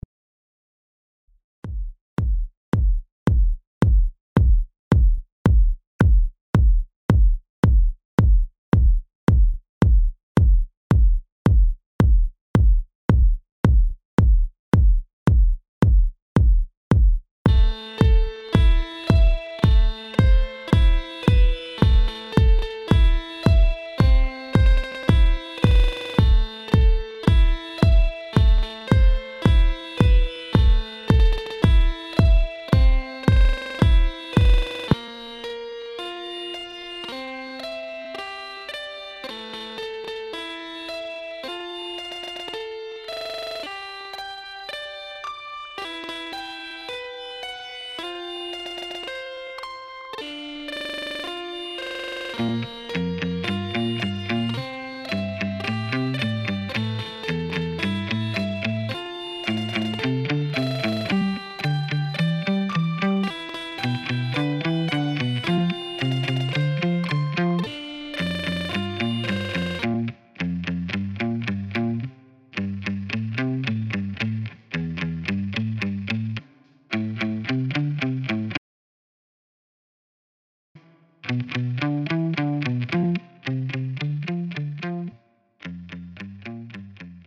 • Жанр: Электронная
У данной композиции отсутствует текст.